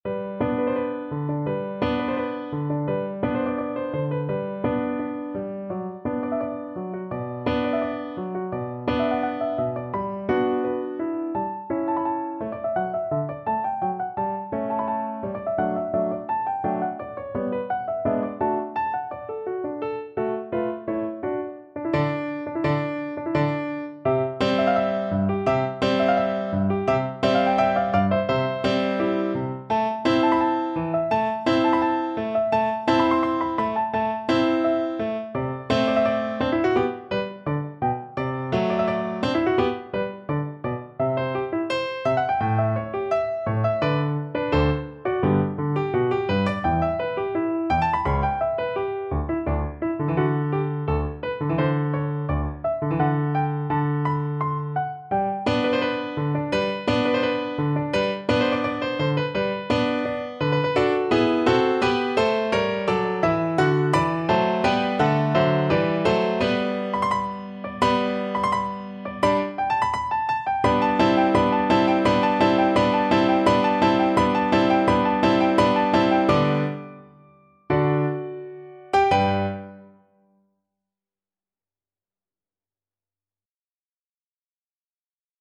Classical Bruch, Max Violin Concerto No.1 (3rd mvt main theme) Piano version
No parts available for this pieces as it is for solo piano.
G major (Sounding Pitch) (View more G major Music for Piano )
4/4 (View more 4/4 Music)
Allegro Energico = 170 (View more music marked Allegro)
Classical (View more Classical Piano Music)